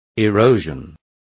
Complete with pronunciation of the translation of erosion.